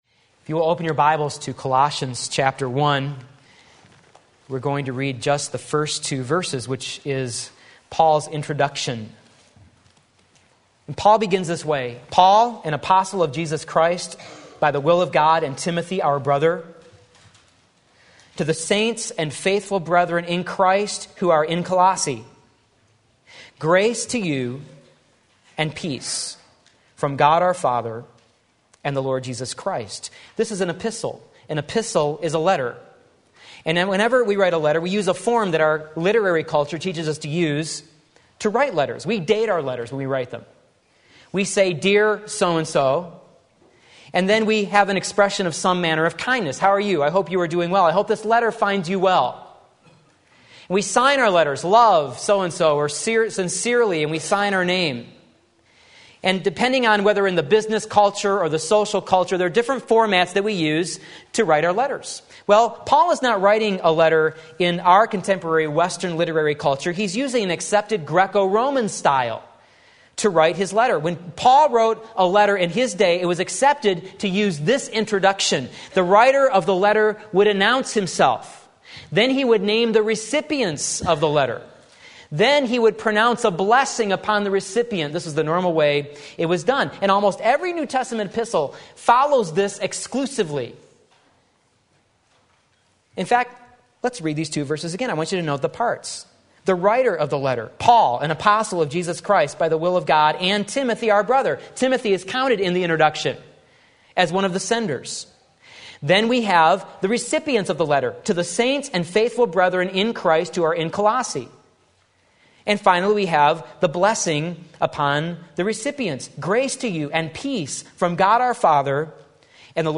Sermon Link
In Christ Colossians 1:1-2 Sunday Morning Service